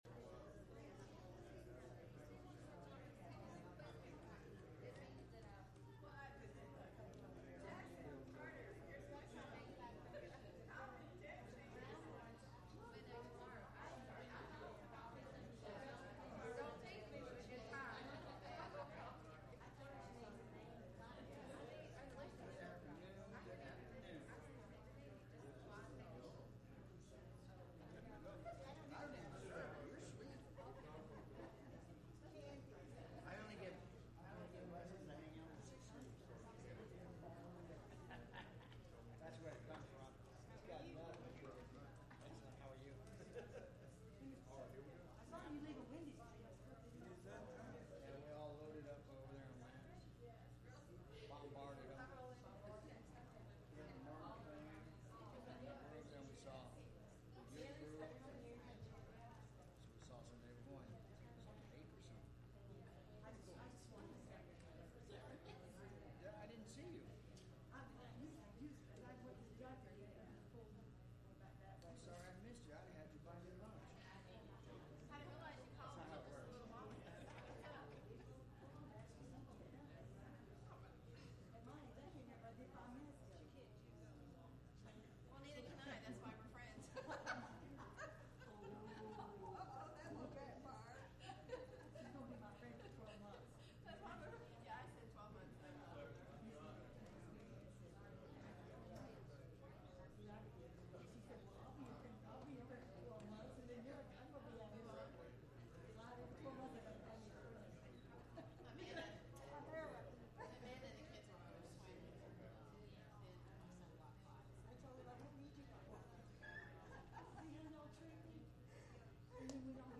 Acts 12:5 Service Type: Sunday Evening « The Word Yet